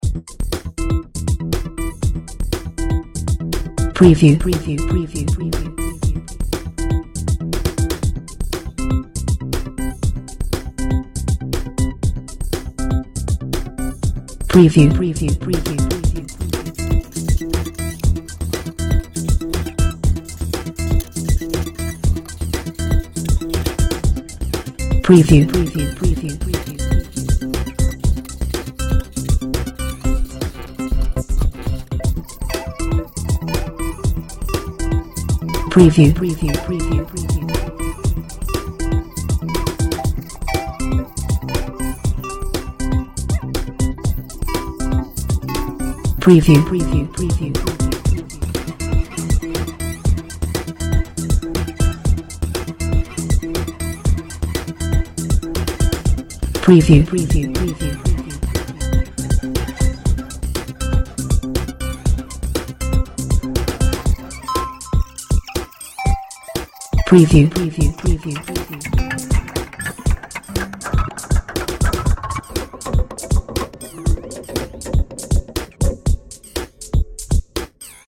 Perfectly looped for continuous performance. http